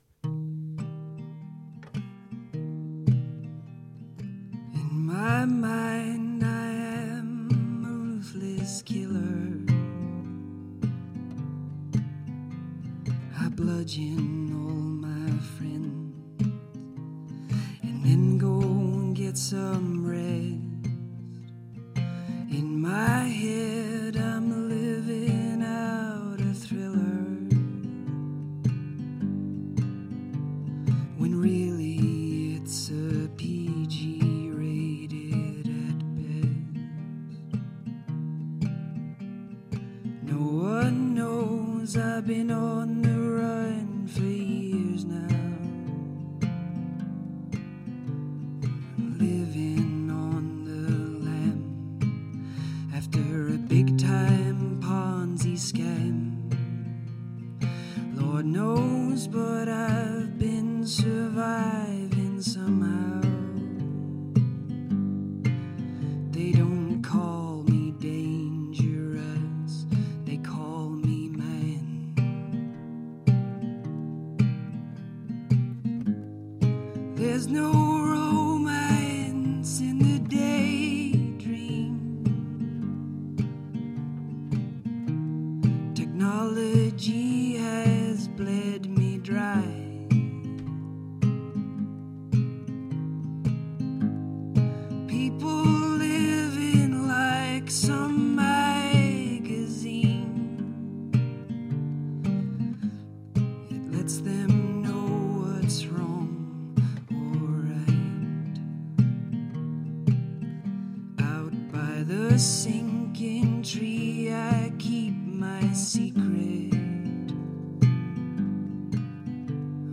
Live music
singer songwriter